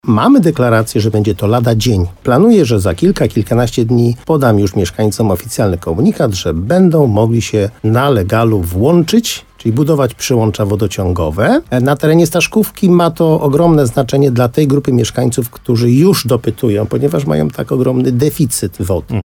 Zakończyła się budowa 10 km sieci wodociągowej w Staszkówce wraz ze zbiornikiem retencyjnym. Wójt gminy Moszczenica Jerzy Wałęga mówił w programie Słowo za słowo na antenie RDN Nowy Sącz, że brakuje jedynie pozwolenia na użytkowanie, które ma wydać Powiatowy Inspektor Nadzoru Budowlanego w Gorlicach.